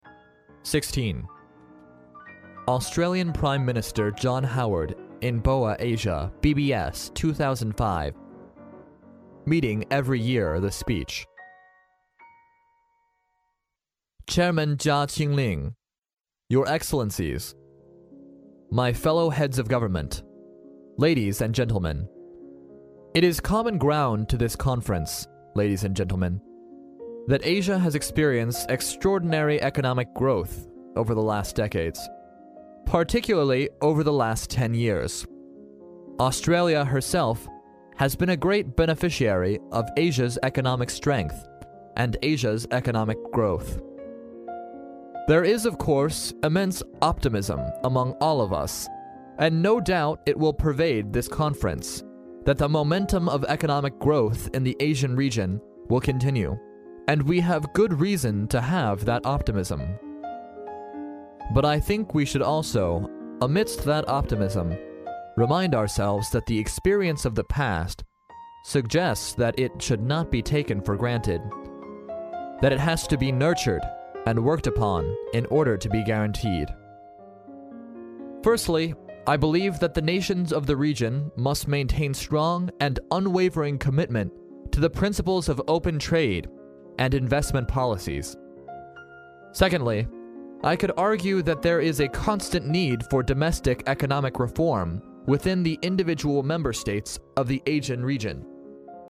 历史英雄名人演讲 第23期:澳总理霍华德博鳌亚洲论坛年会演讲(1) 听力文件下载—在线英语听力室